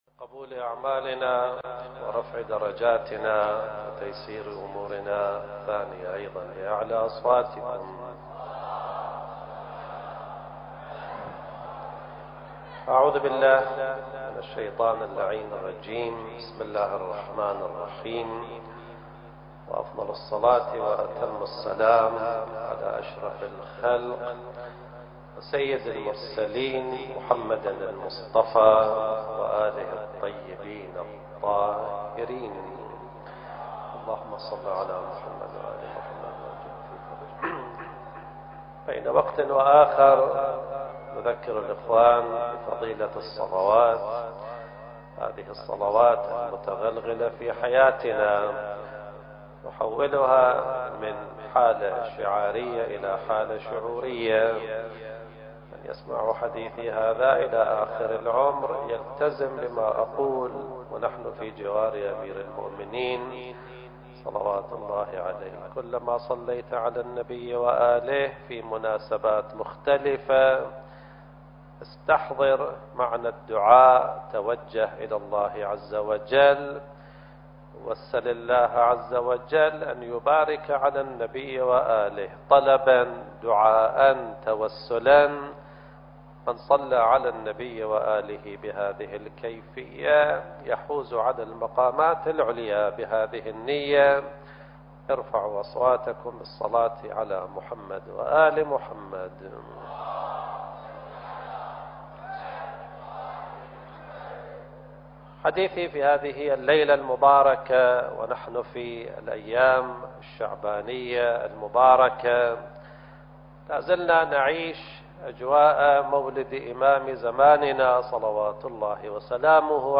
المكان: العتبة العلوية المقدسة - الملتقى الإعلامي المهدوي الثالث التاريخ: 2014